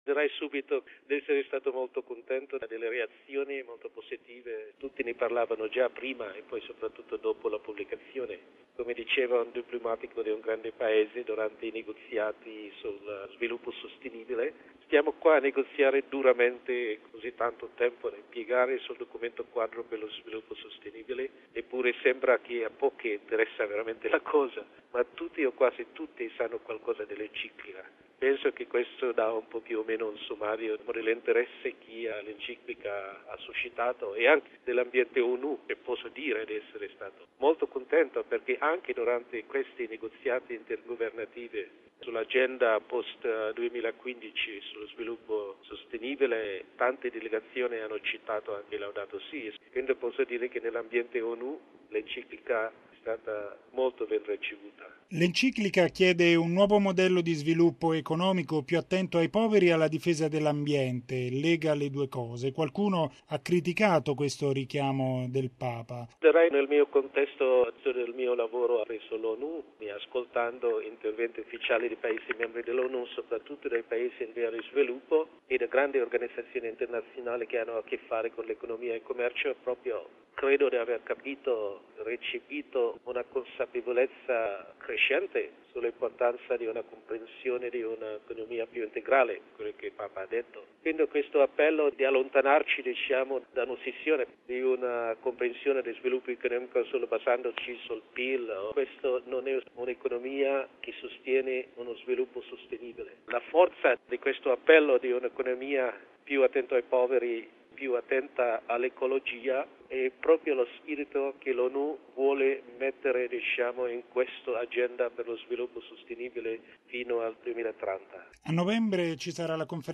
Proprio da qui muove la riflessione dell’arcivescovo Bernardito Auza, osservatore permanente della Santa Sede al Palazzo di Vetro